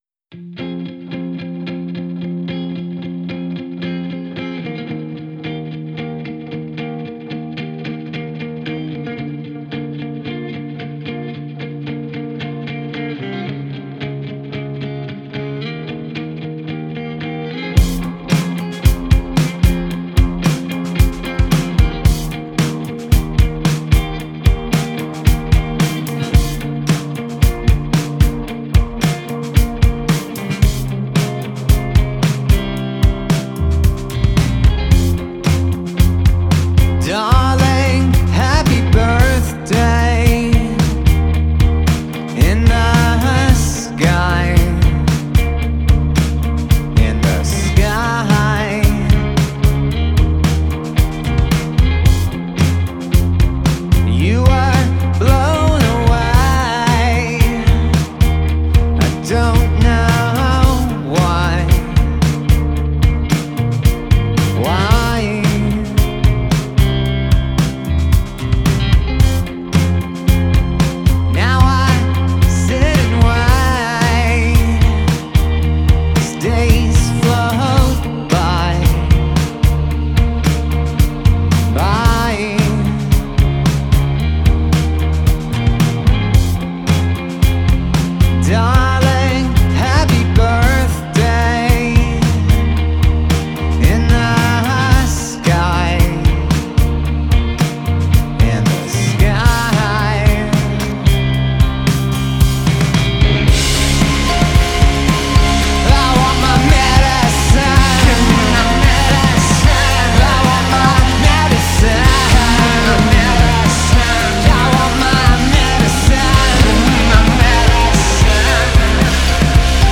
Alternative rock Indie rock